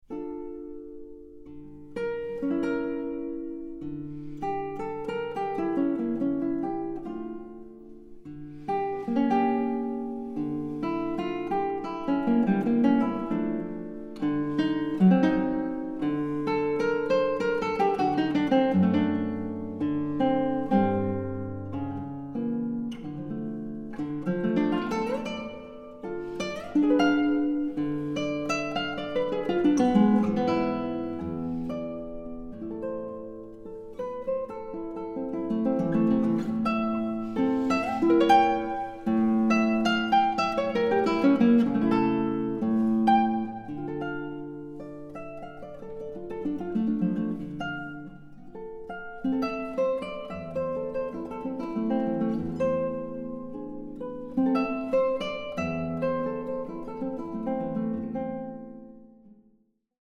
Eine musikalische Collage kanonischer Gitarrenwerke
Gitarre